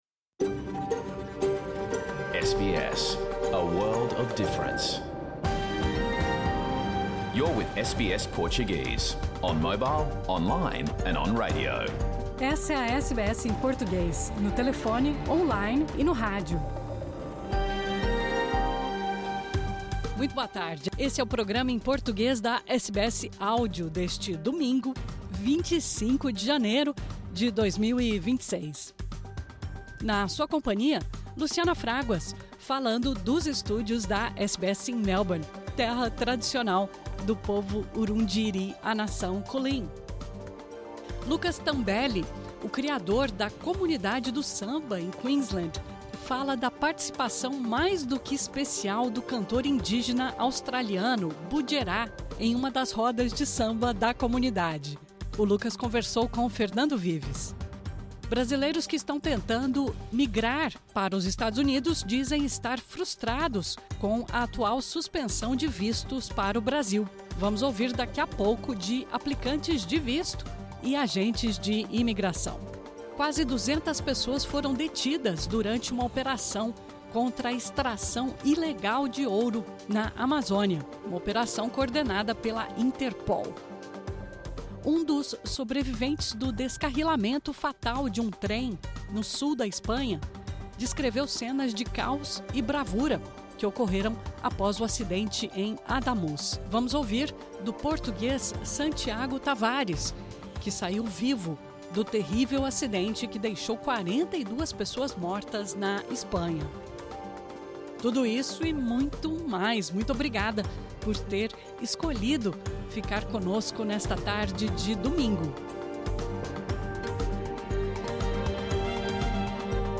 Programa ao vivo | Domingo, 25 de janeiro